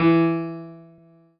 piano-ff-32.wav